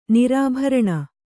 ♪ nirābharaṇa